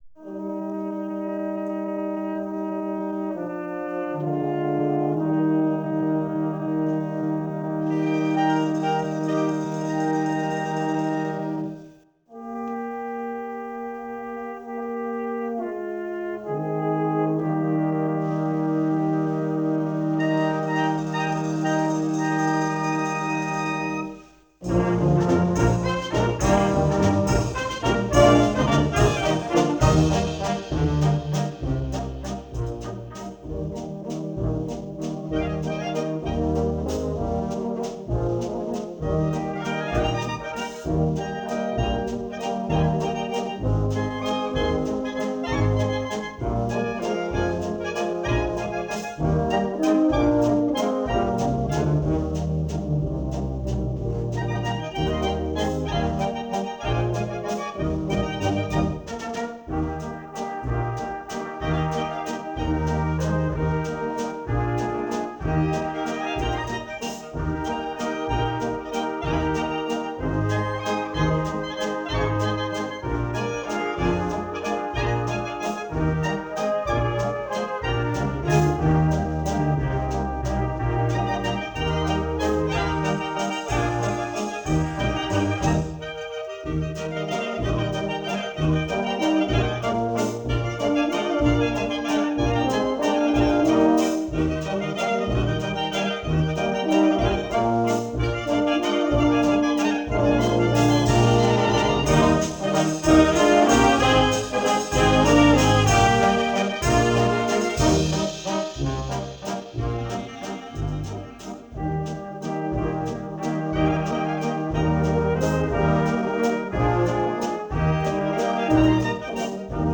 Harmonie/Blaskapelle